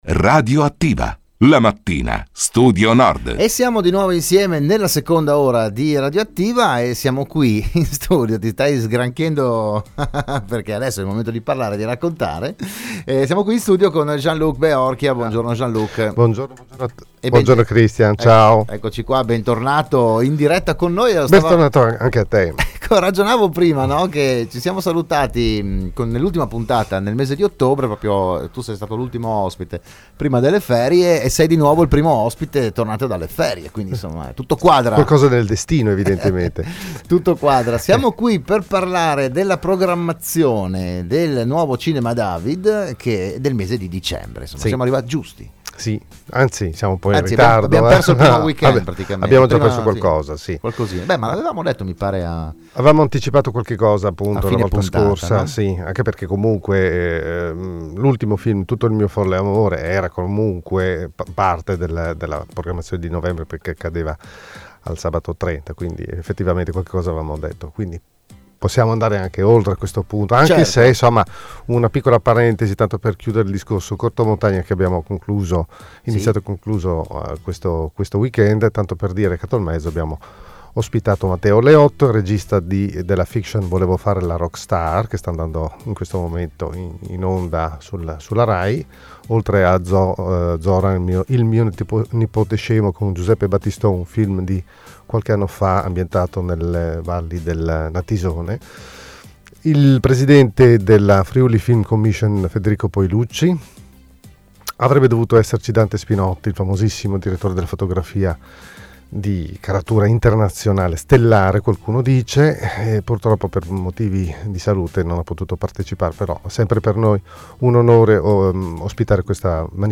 la trasmissione del mattino di Radio Studio Nord